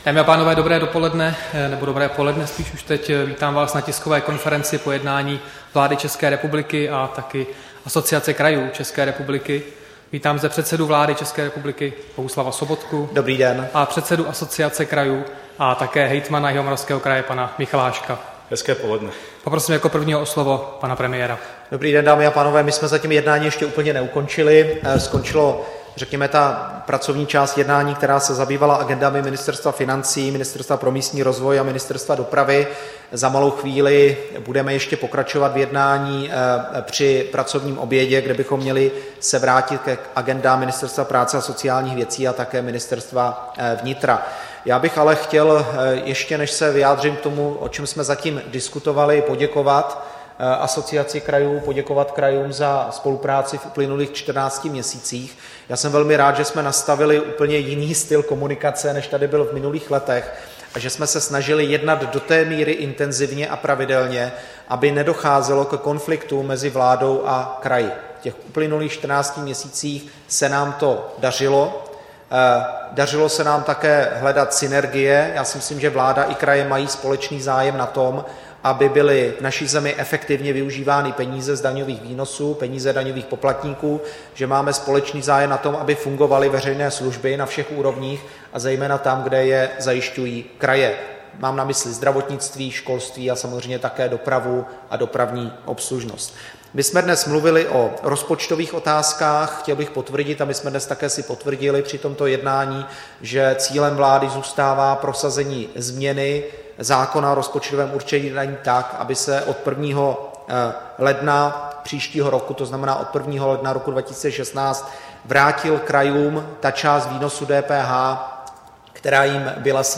Tisková konference po jednání vlády s Asociací krajů ČR, 25. března 2015